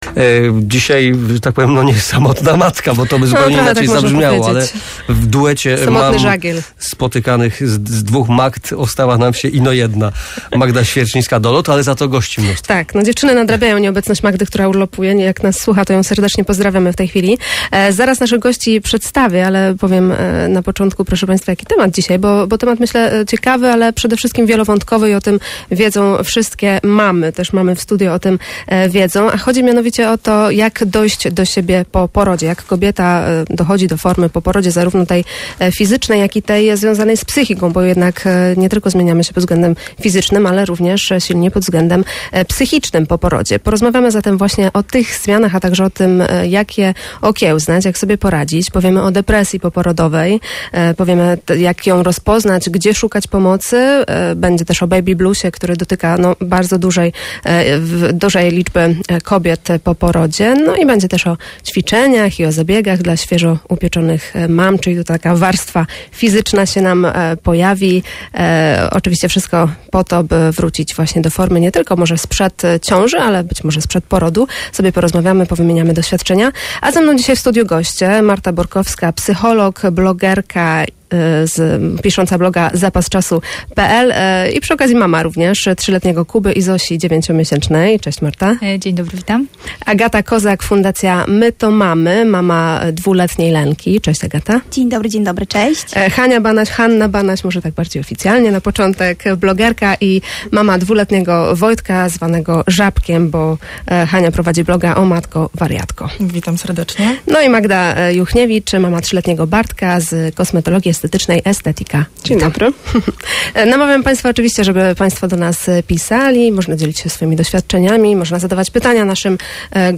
W audycji mowa była o tym, jak dojść do siebie po porodzie, o depresji i baby bluesie, o ćwiczeniach, zajęciach tanecznych oraz zabiegach dla mam, chcących powrócić do formy sprzed porodu. Gośćmi audycji były: